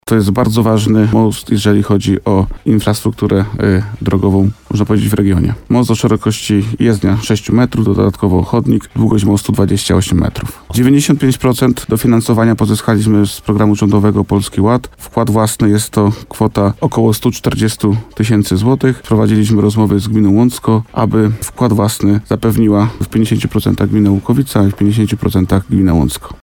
Jak mówił w programie Słowo za Słowo wójt gminy Łukowica, Bogdan Łuczkowski, powstanie inwestycji jest strategiczne.